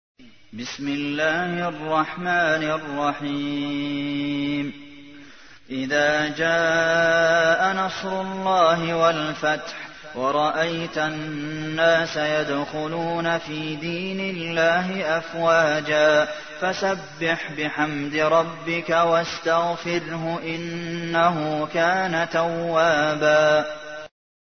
تحميل : 110. سورة النصر / القارئ عبد المحسن قاسم / القرآن الكريم / موقع يا حسين